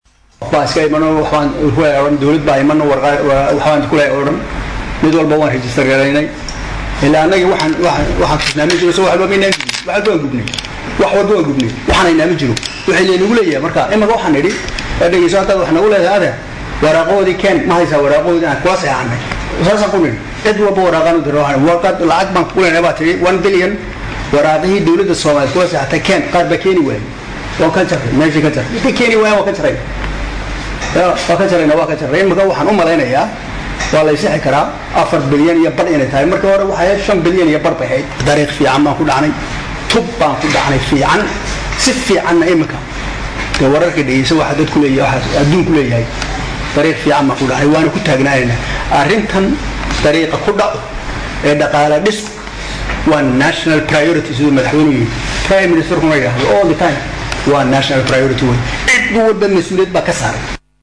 Dhegeyso: Wasiirka Wasaarada Maaliyada oo Ka Hadlay Deymaha Somaliya Lagu Leeyahay